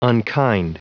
Prononciation du mot unkind en anglais (fichier audio)
Prononciation du mot : unkind